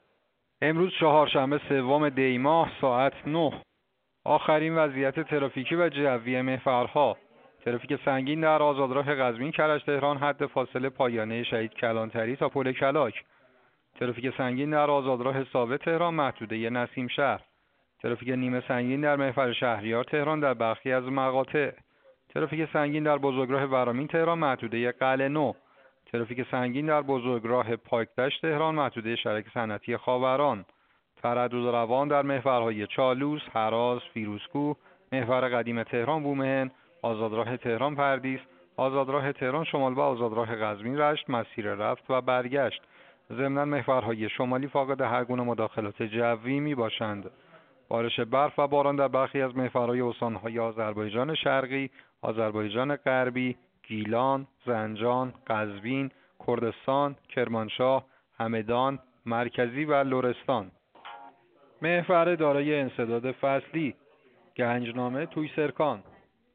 گزارش رادیو اینترنتی از آخرین وضعیت ترافیکی جاده‌ها ساعت ۹ سوم دی؛